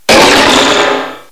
cry_not_mega_mawile.aif